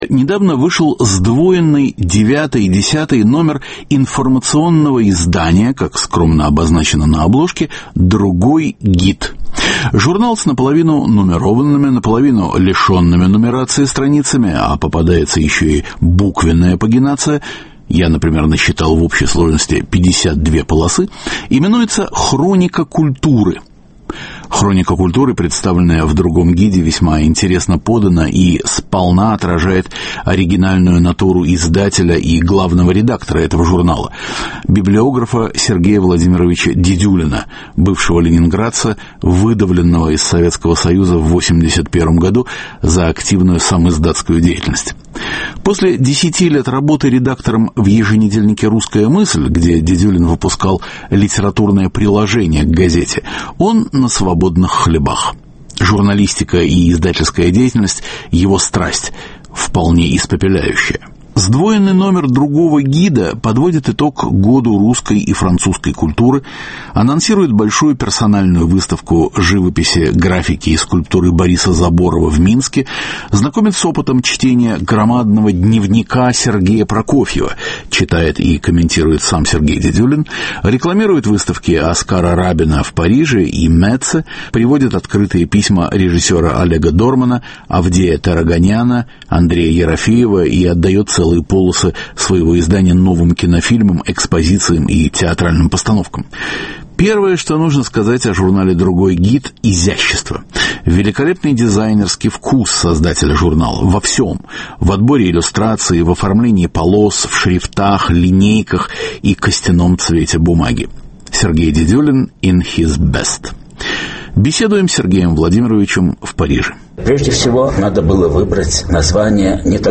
Четыре года русскому парижскому журналу "Другой гид": интервью